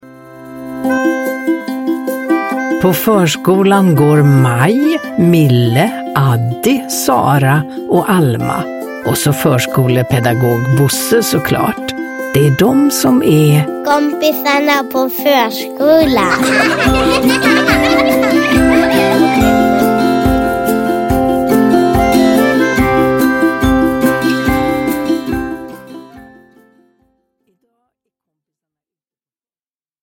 Uppläsare: Ulla Skoog